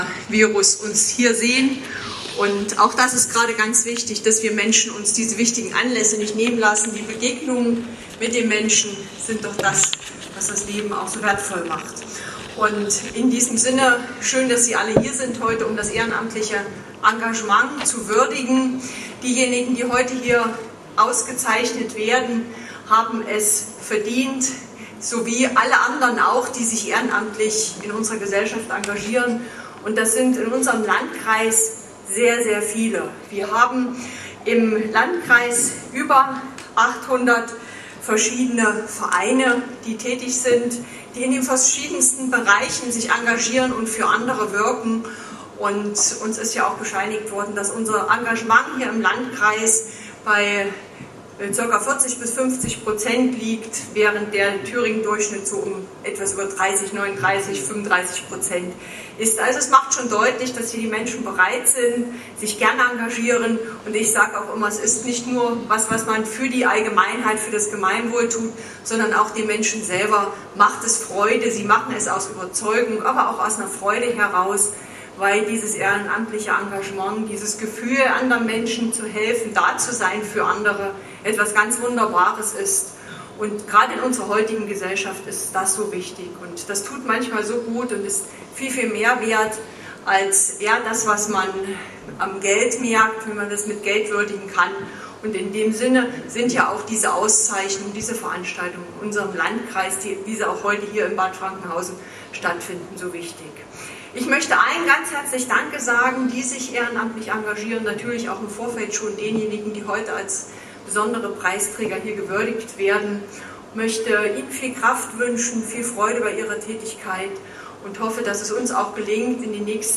Bereits zum 14. Mal fand gestern am Abend in Bad Frankenhausen die Ehrenamts-Gala der Stadt Bad Frankenhausen statt.
In den Grußworten der Landrätin des Kyffhäuserkreises Antje Hochwind-Schneider (SPD) würdigte sie das Ehrenamt. Sie verwies auf die über 800 Verein im Landkreis.
Rede Landrätin